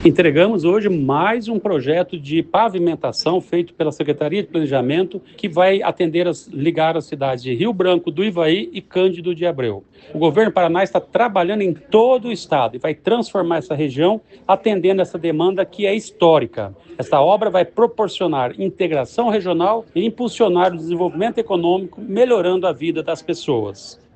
Sonora do secretário do Planejamento, Ulisses Maia, sobre a pavimentação da rodovia entre Rio Branco do Ivaí a Cândido de Abreu